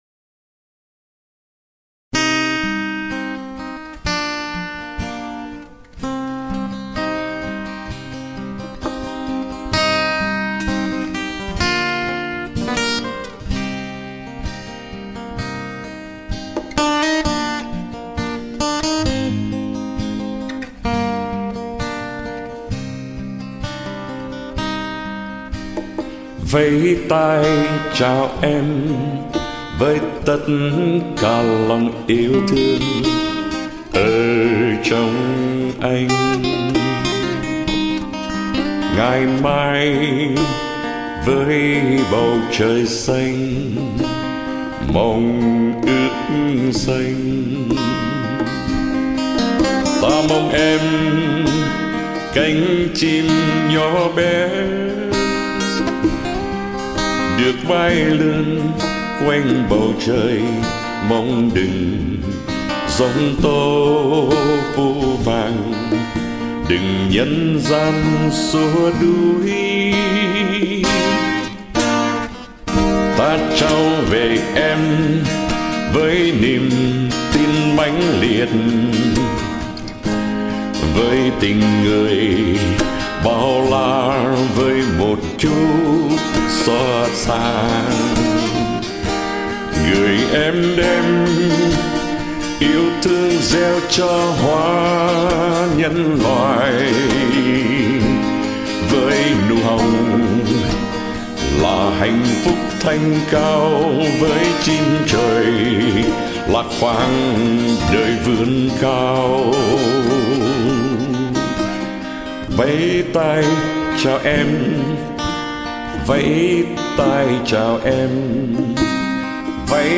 Đàn guitar